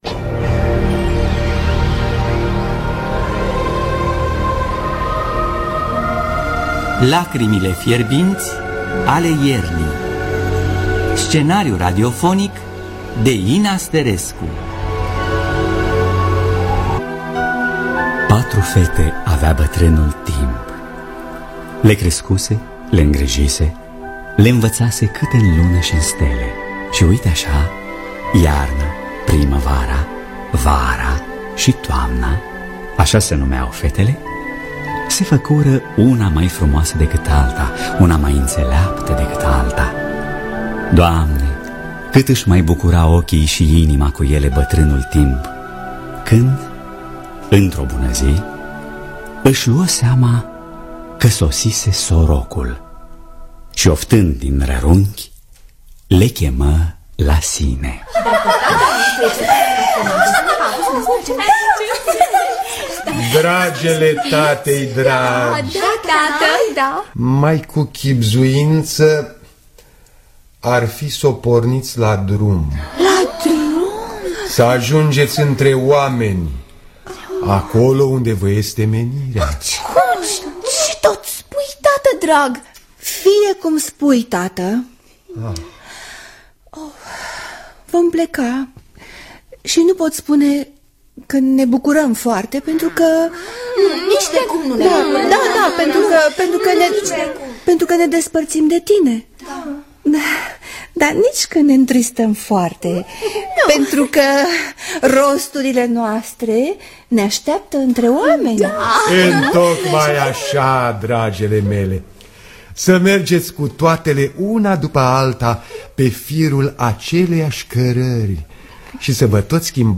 Lacrimile fierbinți ale iernii. Scenariu radiofonic